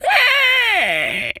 pgs/Assets/Audio/Animal_Impersonations/pig_scream_02.wav at master
pig_scream_02.wav